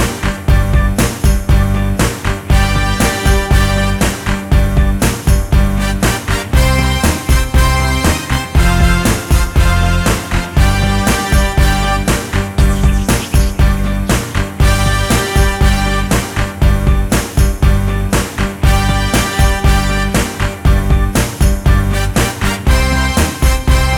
no rap and no Backing Vocals R'n'B / Hip Hop 3:57 Buy £1.50